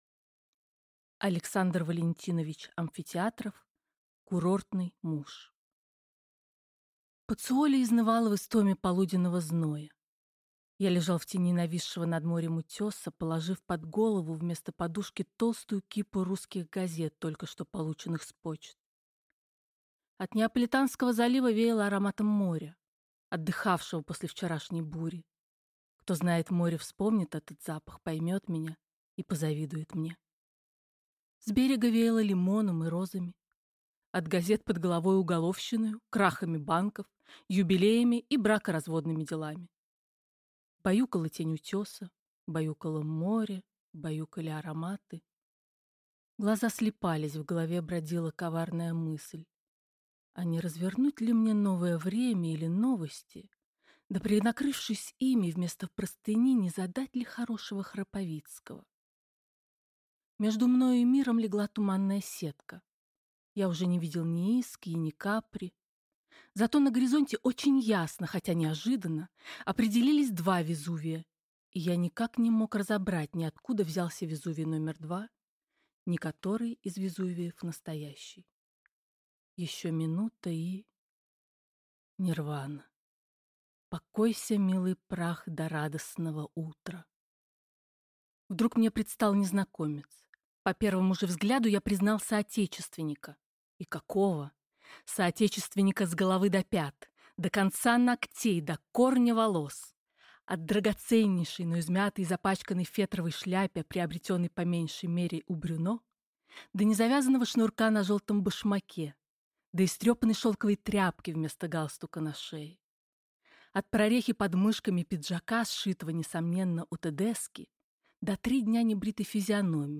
Аудиокнига Курортный муж | Библиотека аудиокниг